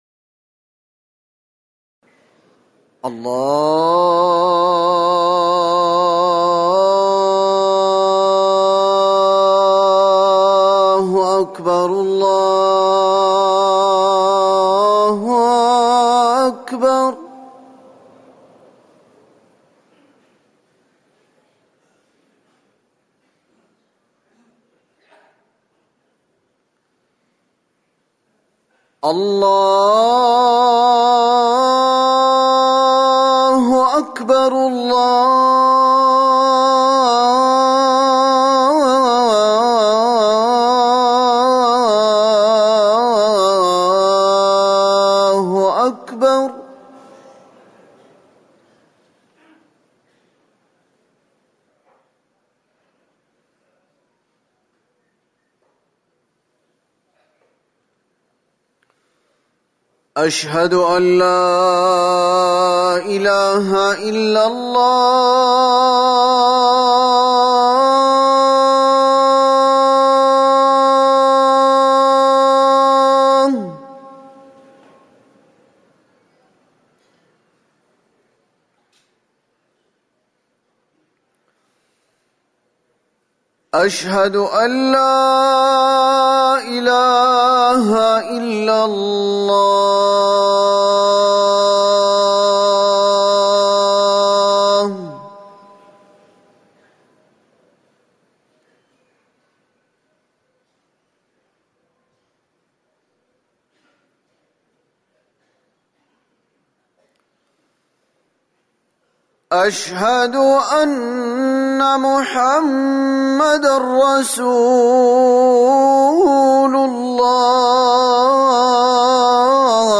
أذان الفجر الأول - الموقع الرسمي لرئاسة الشؤون الدينية بالمسجد النبوي والمسجد الحرام
تاريخ النشر ٨ صفر ١٤٤١ هـ المكان: المسجد النبوي الشيخ